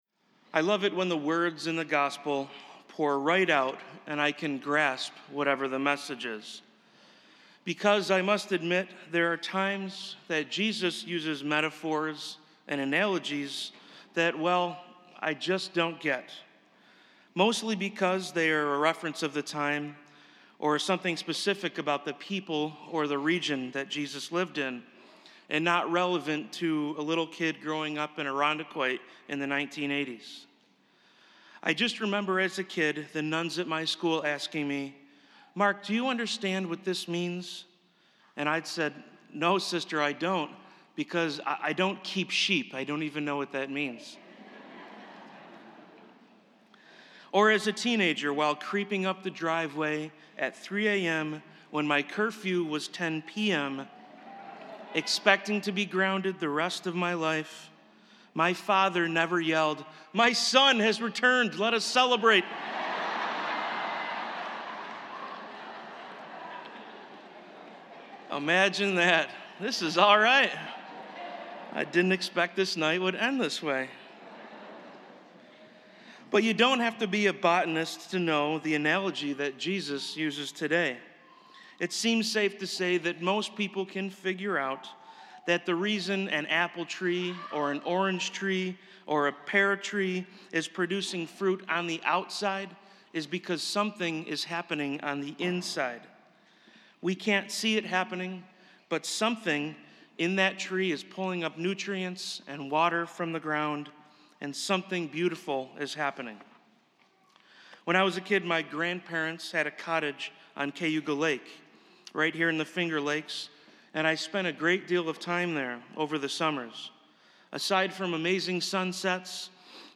Spiritus Christi Mass April 29th, 2018